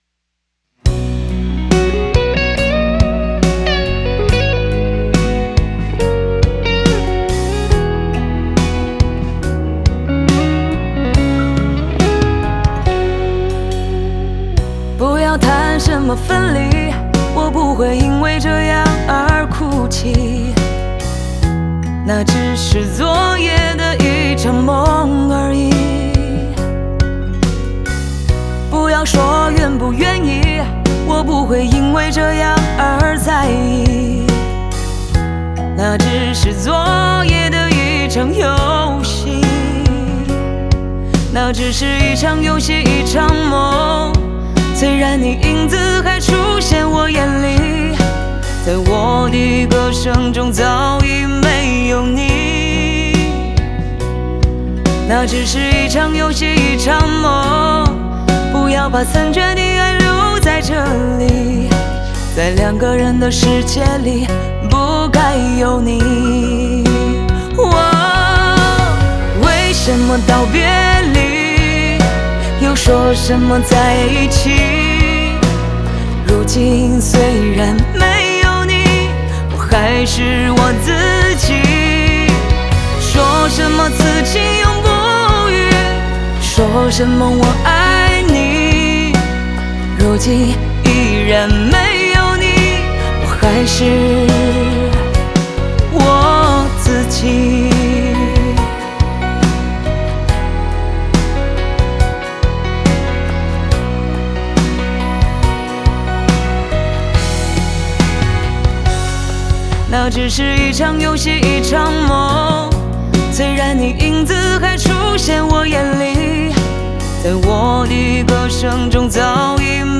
Ps：在线试听为压缩音质节选，